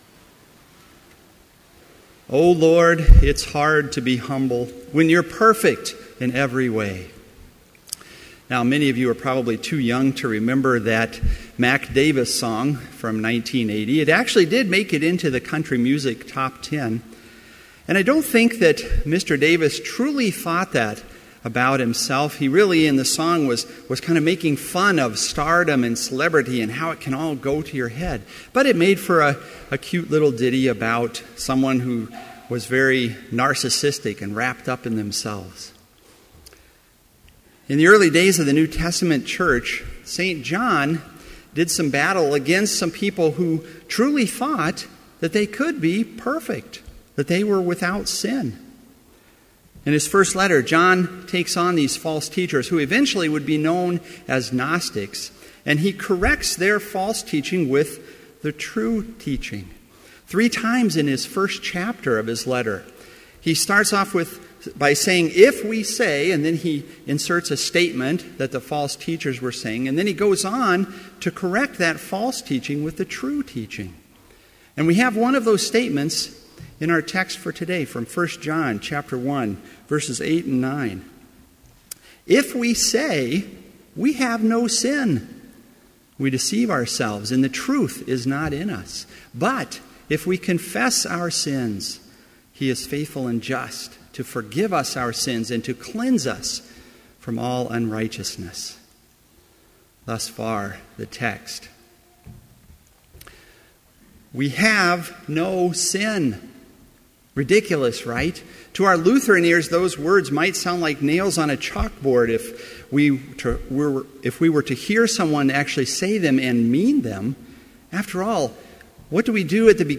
Complete service audio for Chapel - September 4, 2014